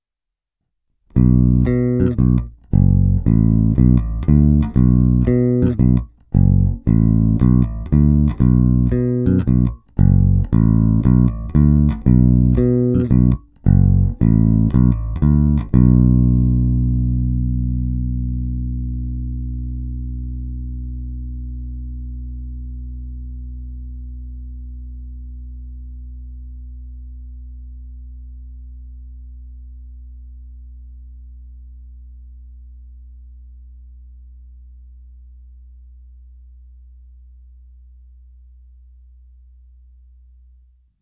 Není-li uvedeno jinak, následující ukázky jsou provedeny rovnou do zvukové karty a jen normalizovány.
Oba snímače